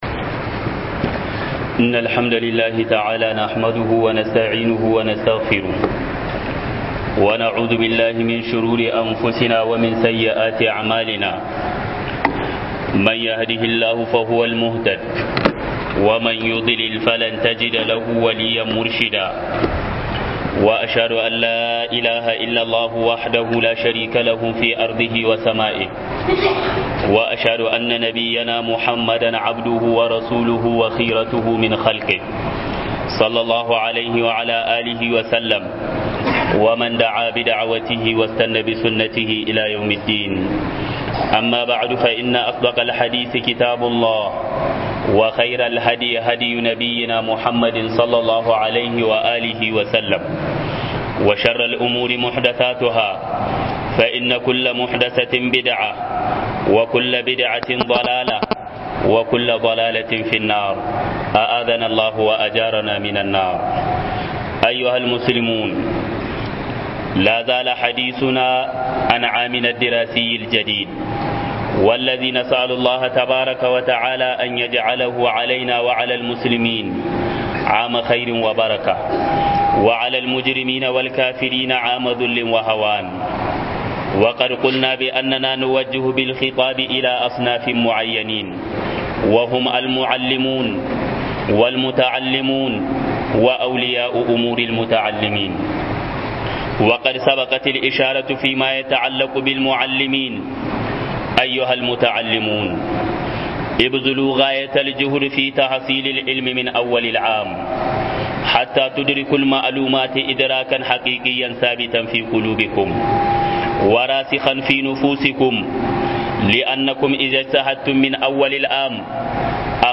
huduba-Malamai-da-uwayen-yara